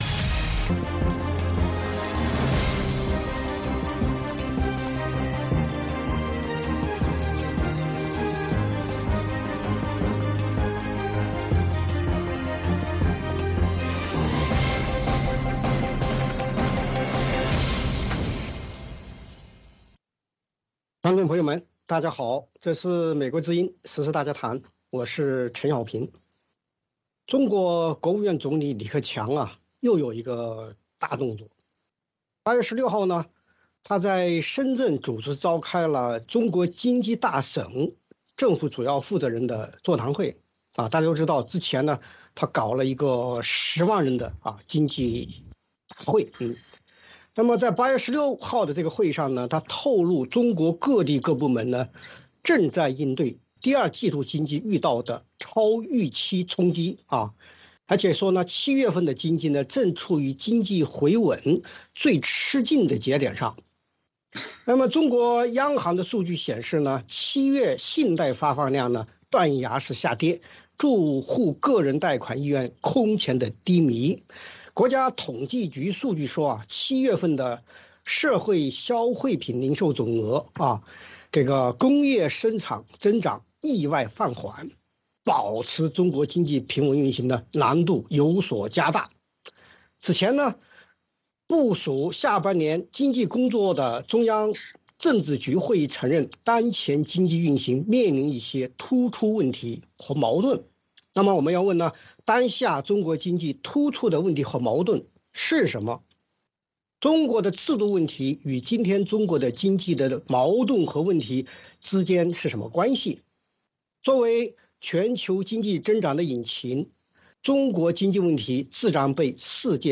VOA卫视-时事大家谈 专访许成钢：西方对中国经济前景判断有哪些思维误区？